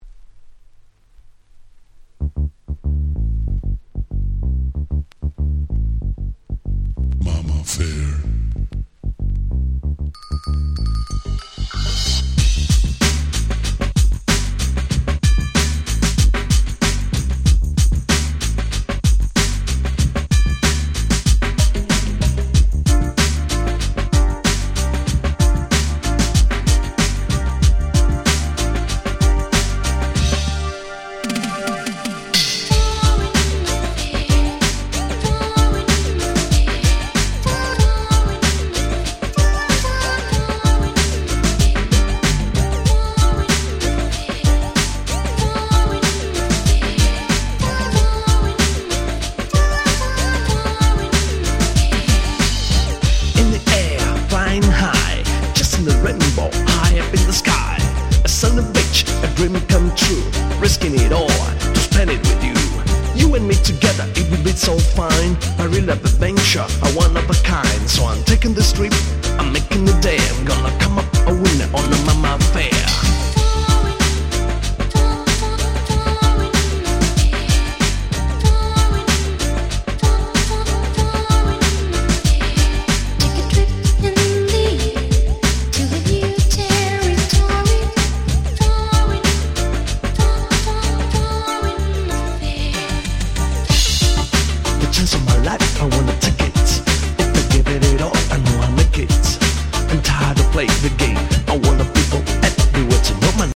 90年という年代柄、内容は流行りのGround Beatが多め。
グラウンドビート グランド Grand グラビ